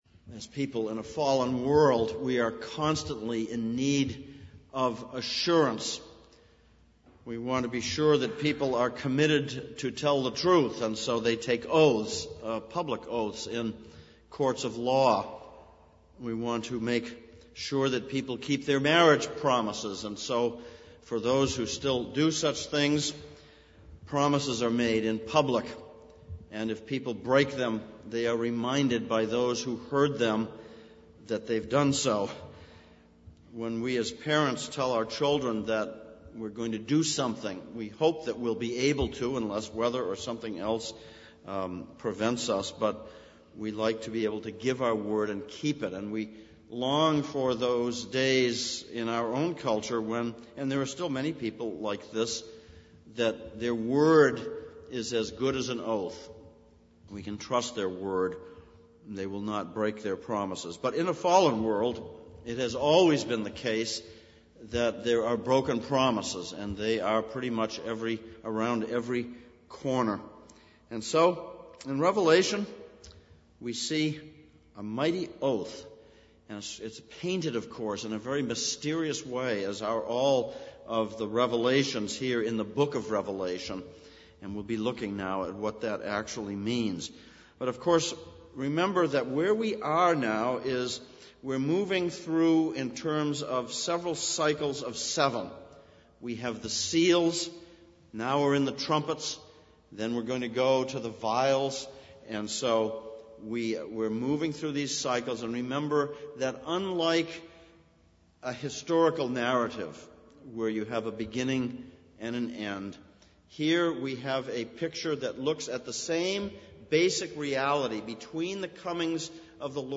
Daniel 12:1-13 Service Type: Sunday Evening Sermon on Revelation 10:1-7 « 4.